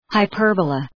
Προφορά
{haı’pɜ:rbələ}